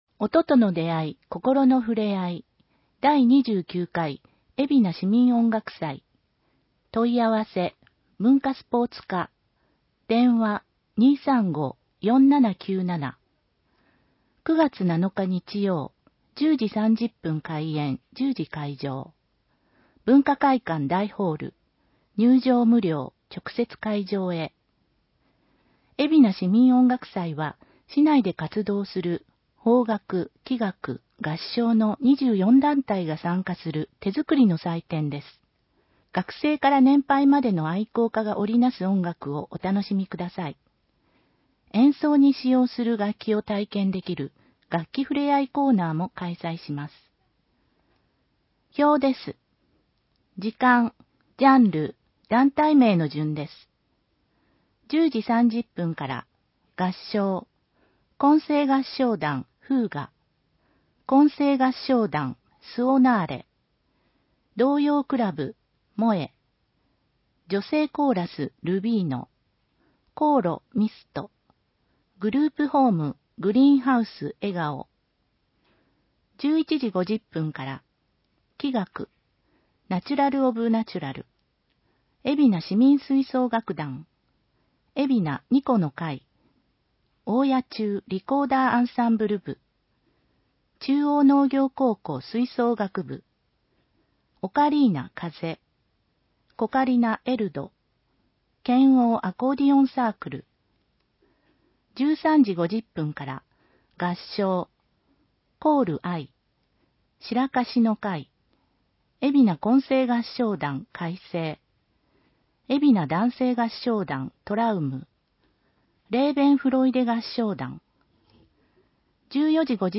広報えびな 平成26年8月15日号（電子ブック） （外部リンク） PDF・音声版 ※ 音声版は、音声訳ボランティア「矢ぐるまの会」の協力により、同会が視覚障がい者の方のために作成したものを登載しています。